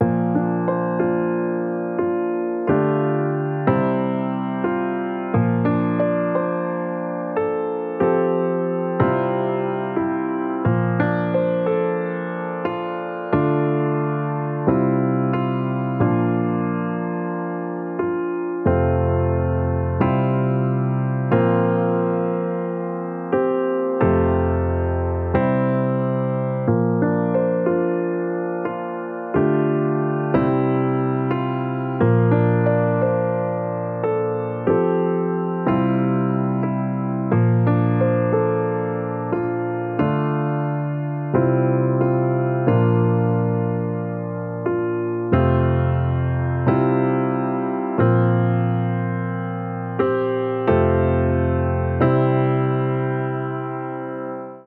Emotional